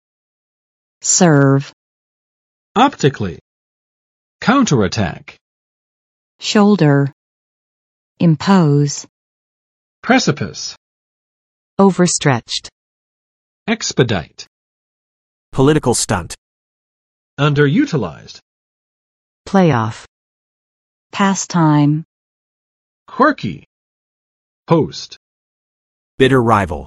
[sɝv] v. 伺候